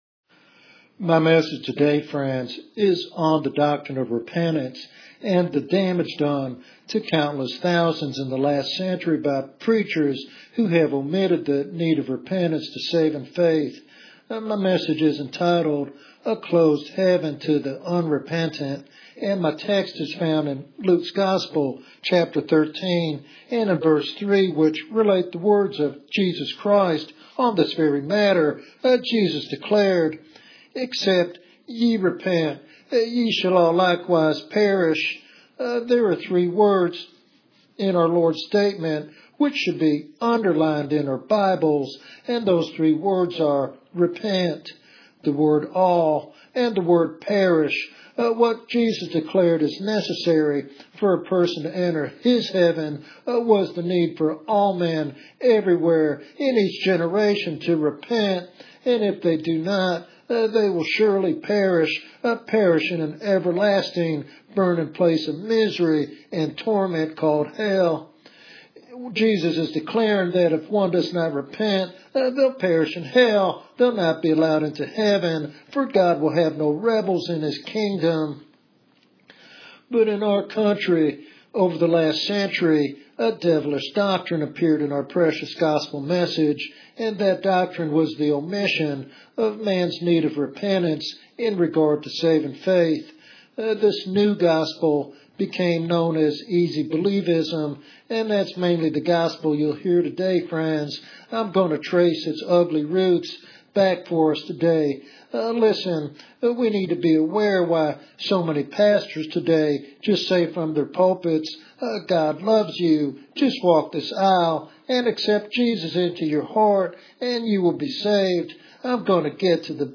This sermon is a clarion call to embrace genuine faith that includes turning from sin.